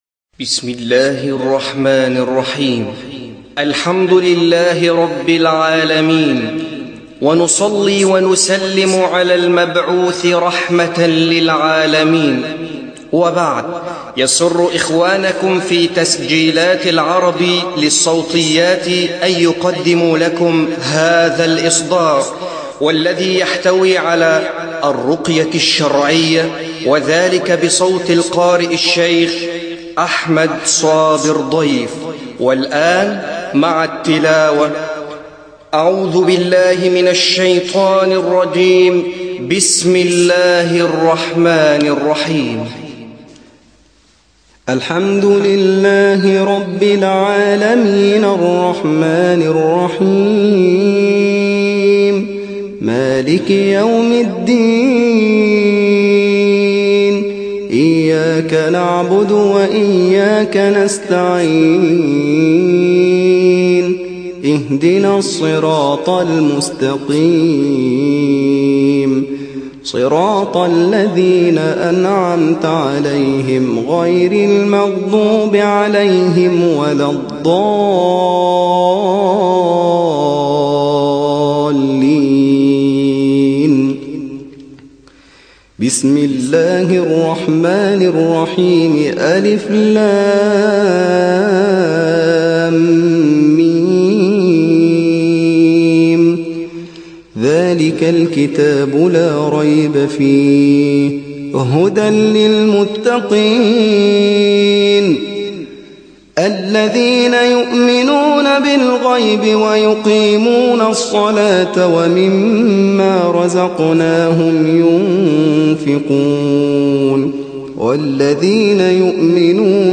الرقية الشرعية